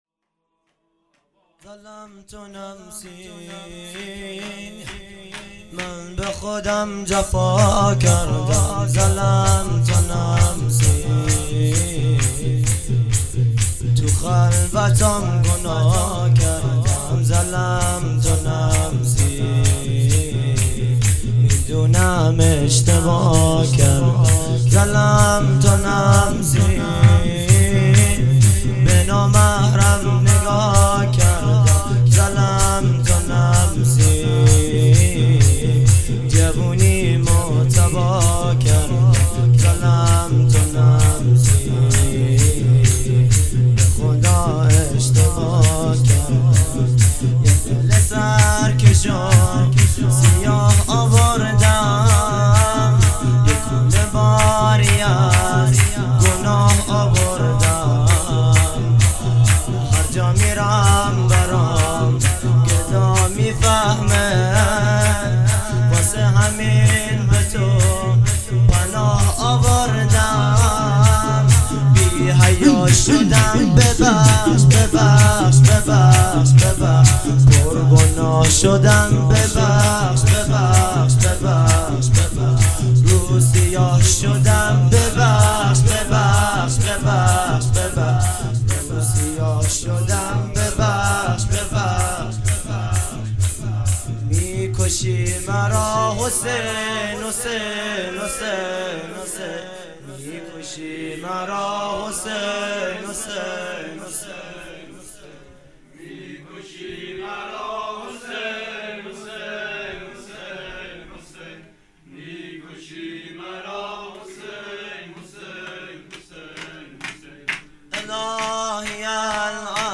شور
مراسم هفتگی ۱-۱۲-۹۸